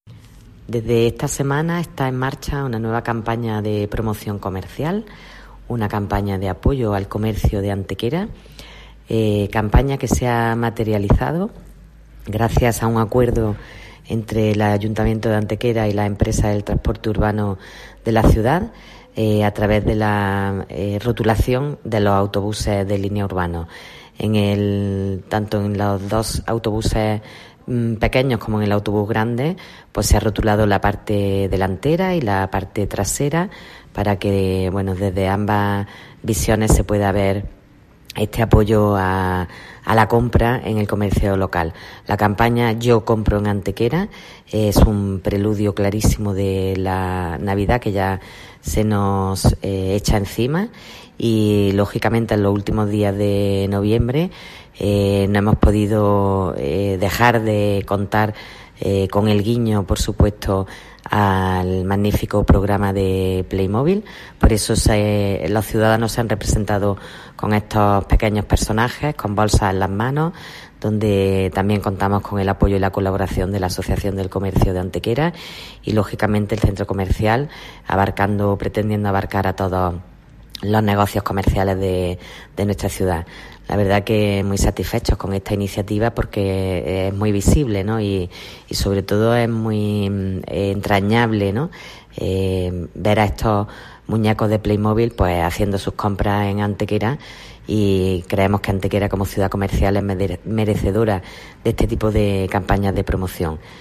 La teniente de alcalde Belén Jiménez se considera satisfecha con esta nueva campaña promocional impulsada directamente por el Ayuntamiento en la que destaca su visibilidad y el hecho de ser entrañable al hacer protagonistas indirectos a figuras de Playmobil. Cortes de voz B. Jiménez 710 kb Formato: mp3